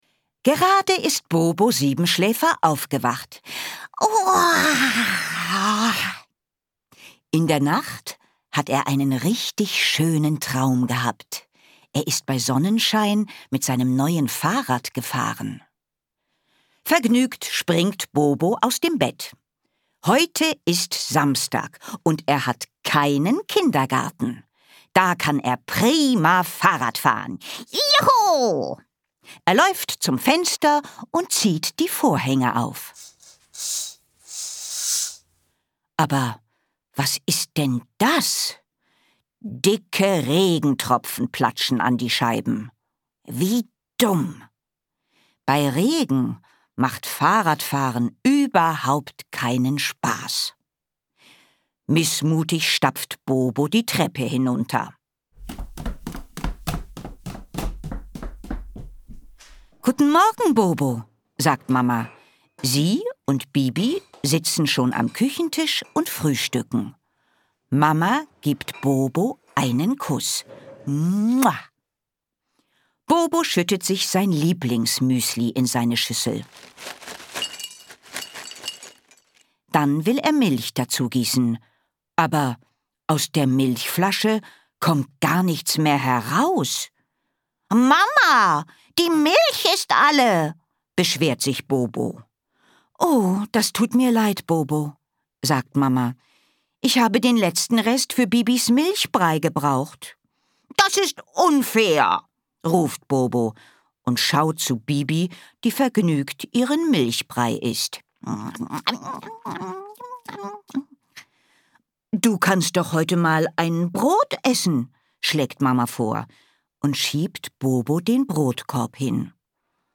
Bobo Siebenschläfer ist stinksauer. Geschichten für ganz Kleine mit KlangErlebnissen und Musik